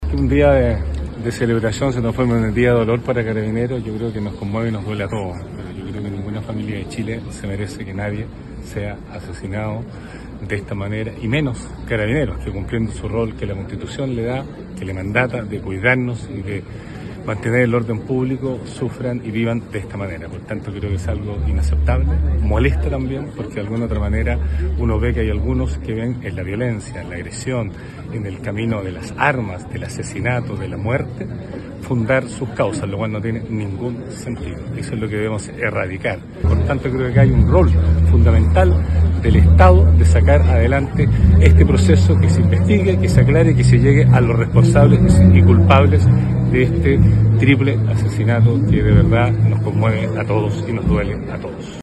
El gobernador Patricio Vallespín lamentó lo sucedido donde sectores minoritarios optan por la violencia como método de imponer sus posiciones, por lo que debe imperar es la reacción del Estado en su conjunto para demostrar que la paz es el camino.